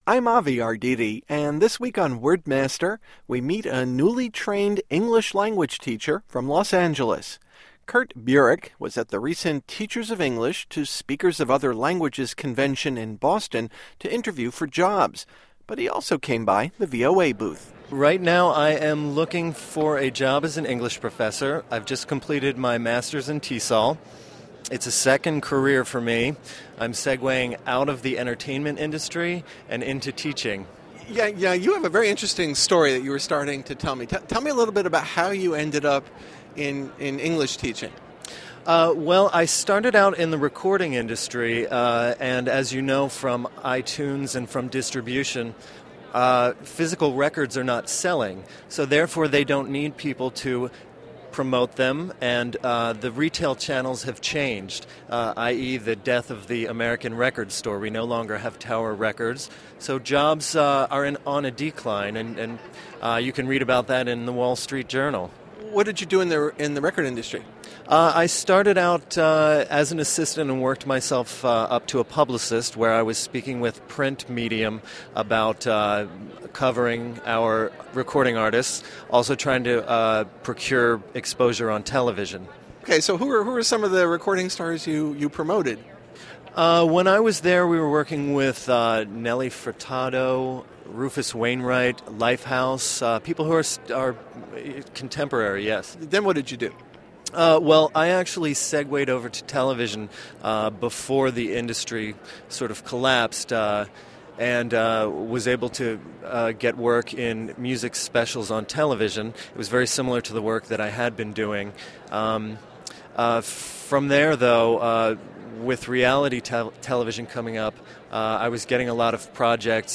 Here are two VOA interviews recorded at our national conference, TESOL.